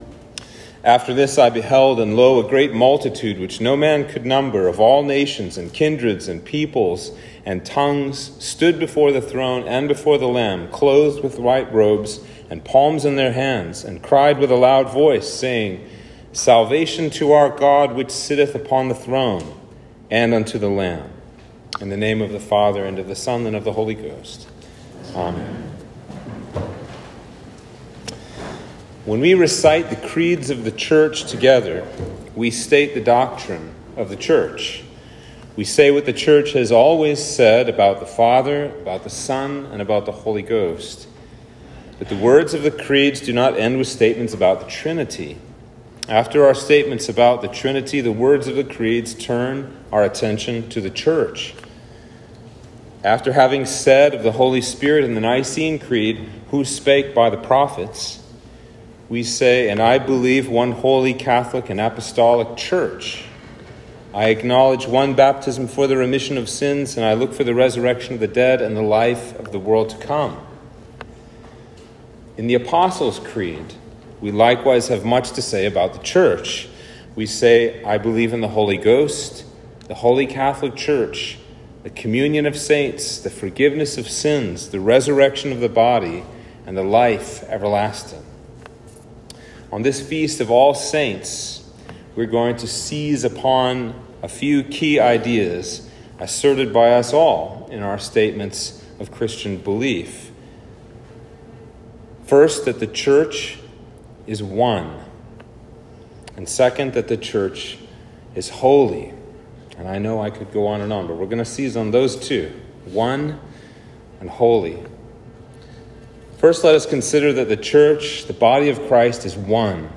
Sermon for All Saints